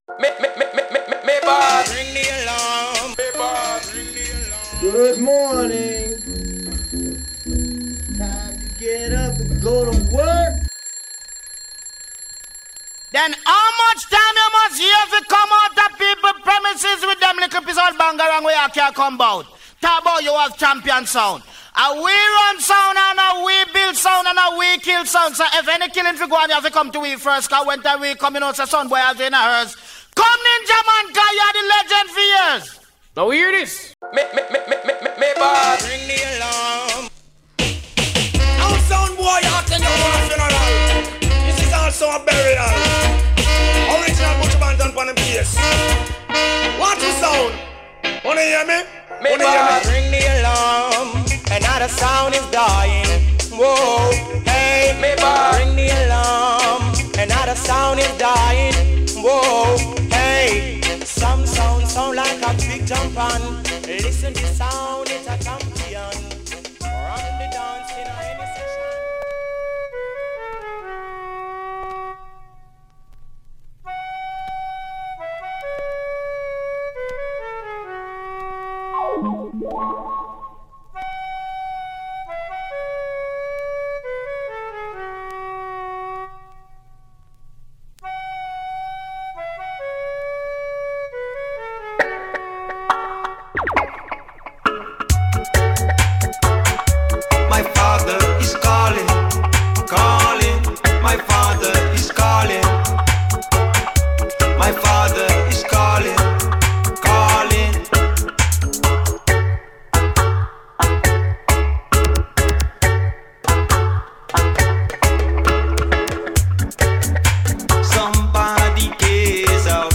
Télécharger en MP3 dub , reggae Laisser un commentaire Laisser un commentaire Annuler la réponse Votre adresse e-mail ne sera pas publiée.